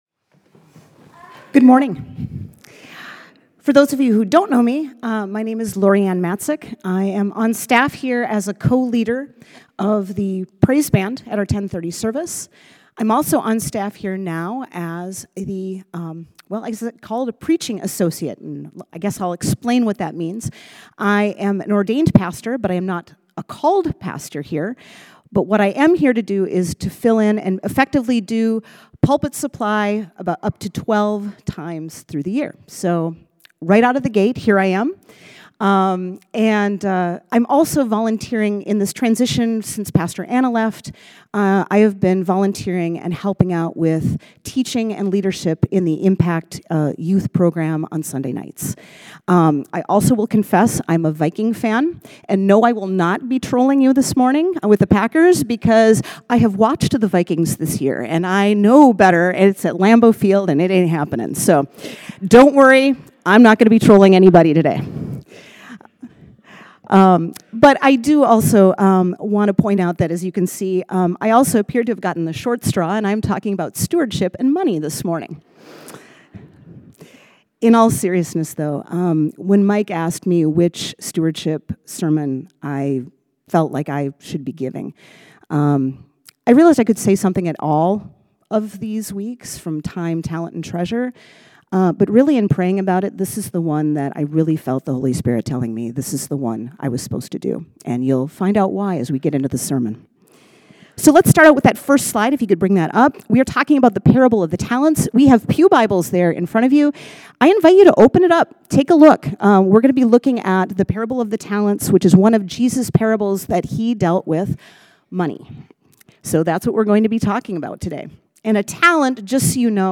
Sermon Series: Open Hands, Full Hearts